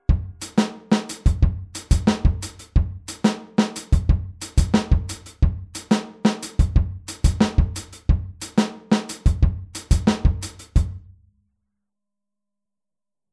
Hier spielen wir den Offbeat mit der rechten Hand wieder auf dem HiHat.
Groove11-16off.mp3